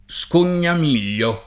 [ S kon’n’am & l’l’o ]